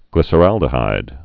(glĭsə-răldə-hīd)